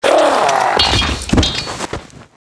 壮汉死亡倒地zth070518.wav
通用动作/01人物/02普通动作类/壮汉死亡倒地zth070518.wav